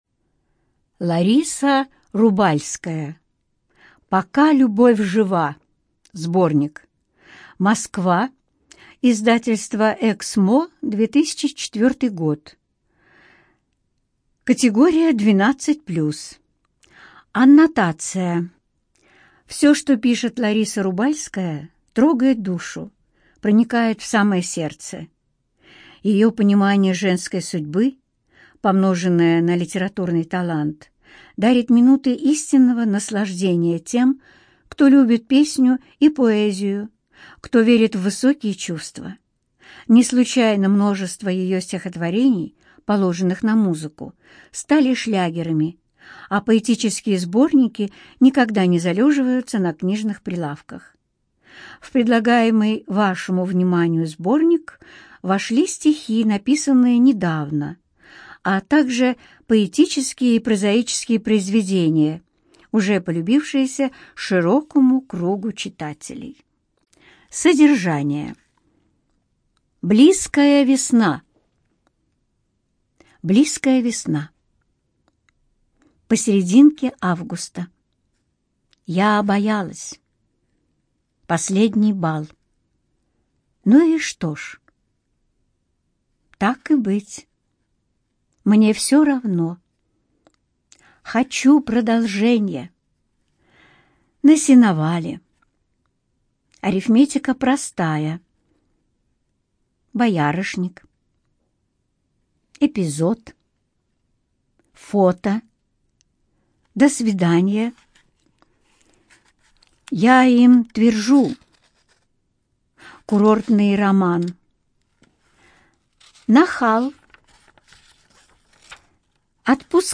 ЖанрПоэзия
Студия звукозаписиЛогосвос